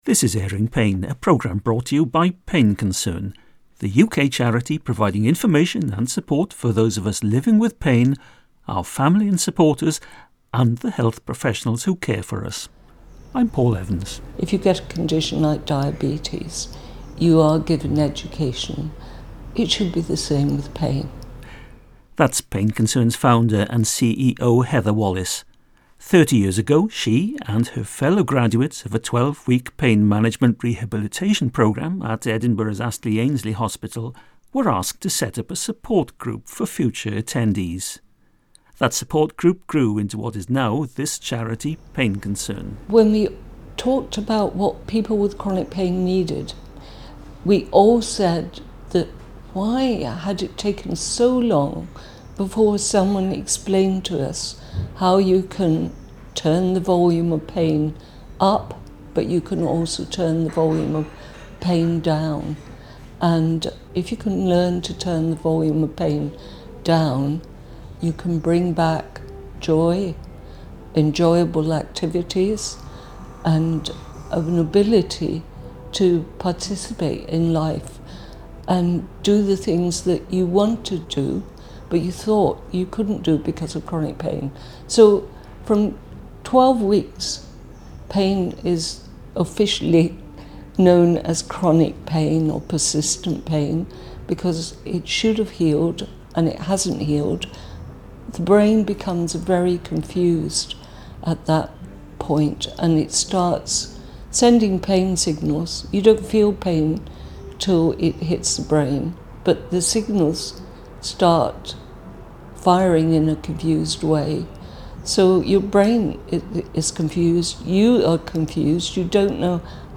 This episode explores the transformative impacts of pain education classes. Featuring excerpts from a live education session, here we look at how a brief, free course—delivered by trained volunteers with lived experience—is empowering people to navigate life with chronic pain. Listen to hear how patients are learning more about their pain and the toolbox of techniques available to manage it.